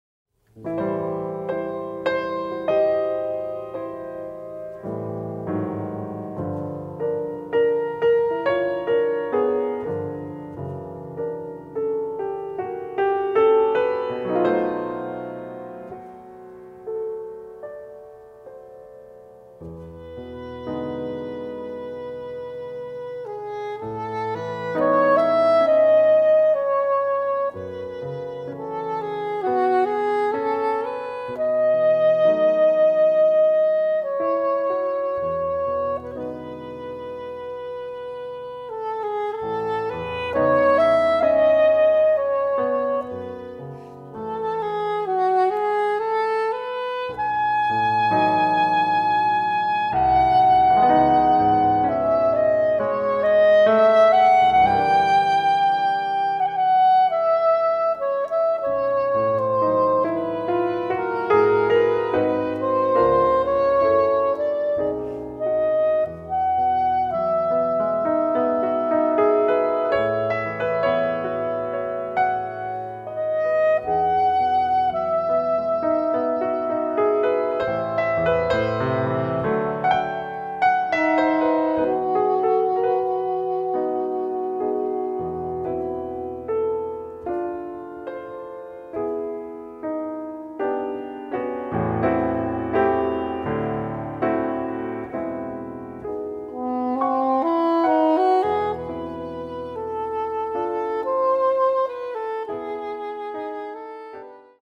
any melodic instrument & piano